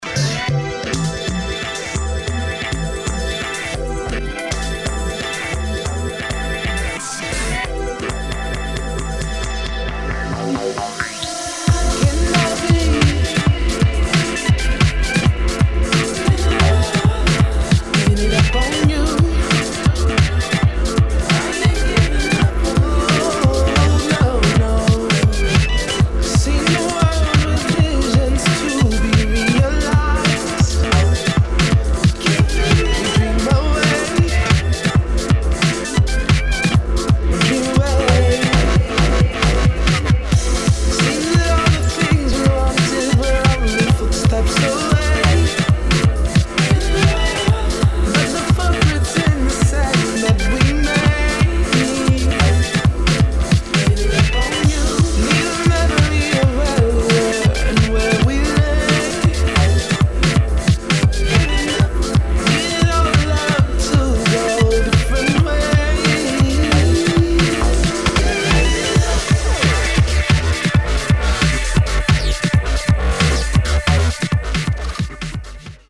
[ DUBSTEP / TRAP / GRIME ]